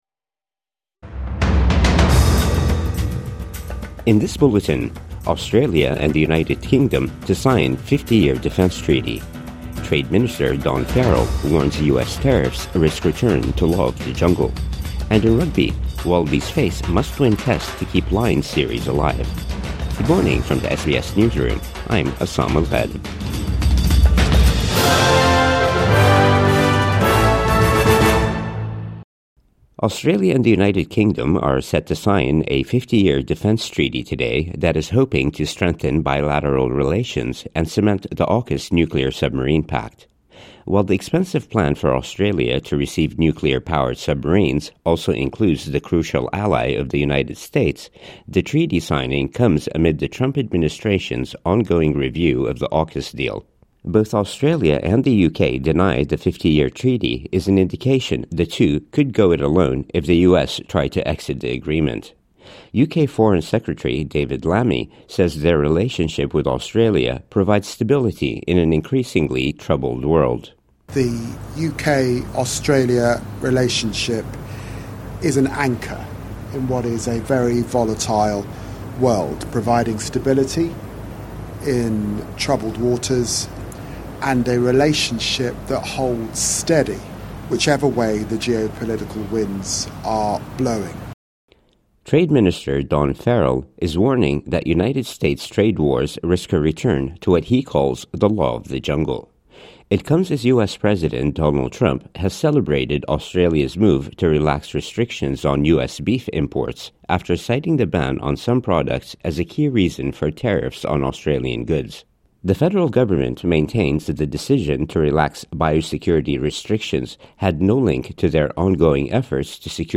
Australia and United Kingdom to sign 50-year defence treaty | Morning News Bulletin 26 July 2025